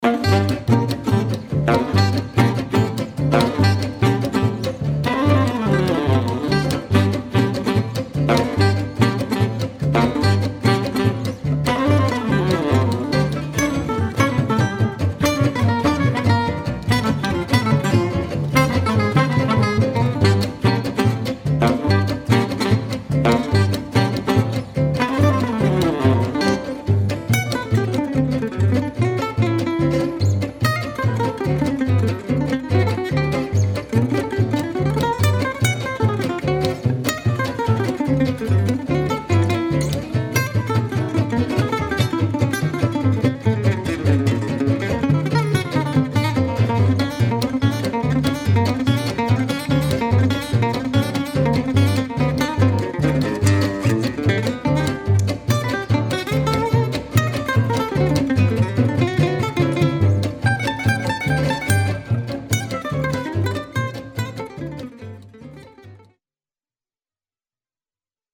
guitare solo
saxophone ténor
guitare rythmique
contrebasse